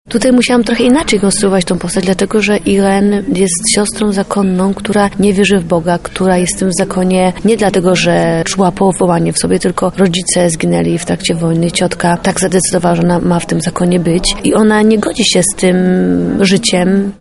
– To nie była łatwa postać do zagrania– mówi Joanna Kulig, odtwórczyni jednej z głównych ról.